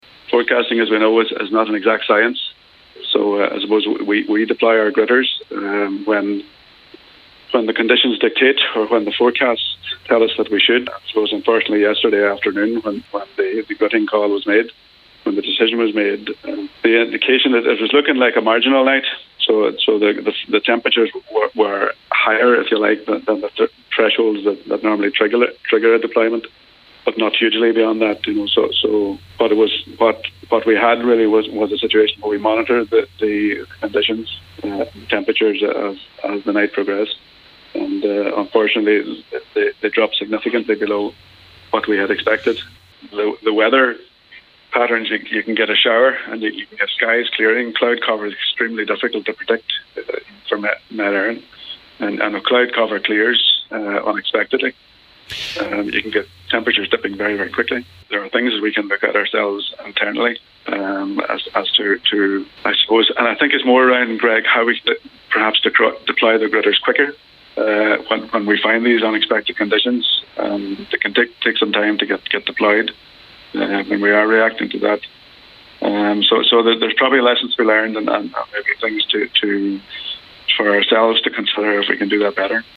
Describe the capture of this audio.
spoke on today’s Nine Till Noon Show